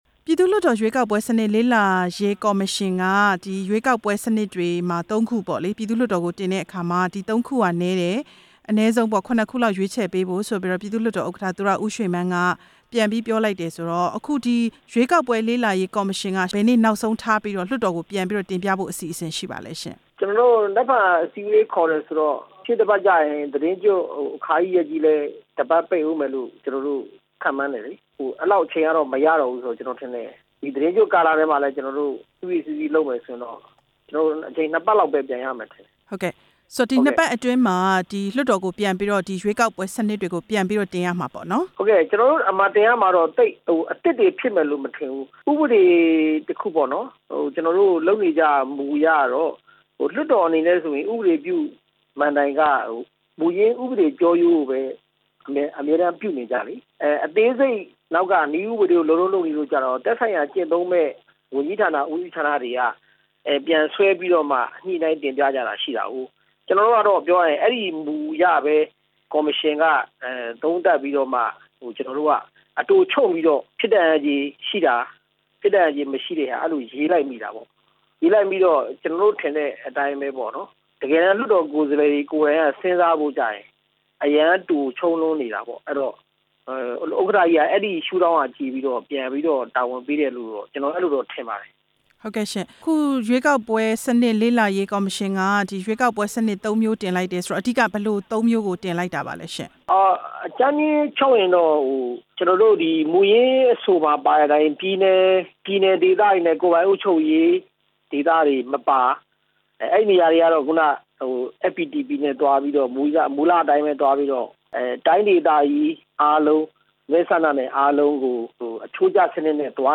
လွှတ်တော်ကိုယ်စားလှယ် ဦးဝင်းသန်းနဲ့ မေးမြန်းချက်